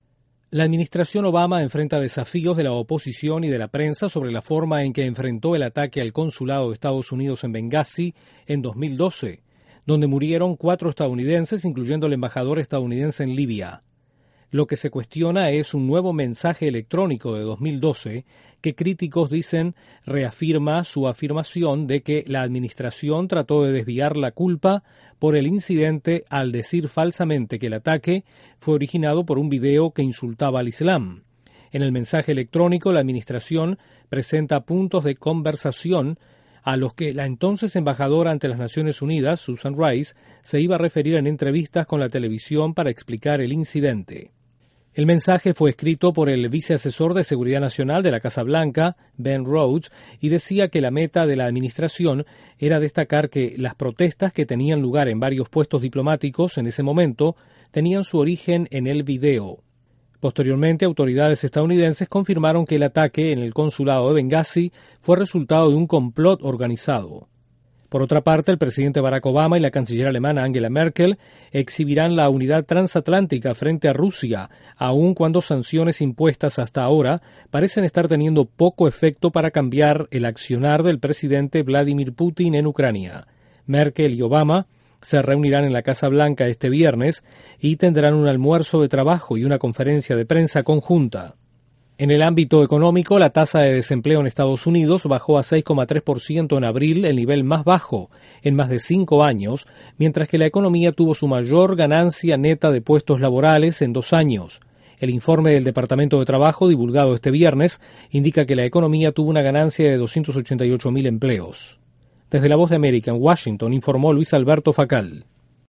La Casa Blanca enfrenta el desafío de la oposición republicana por el mortal ataque al consulado en Benghazi, Libia, en 2012. Ésta y otras noticias desde la Voz de América en Washington